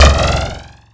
ion.wav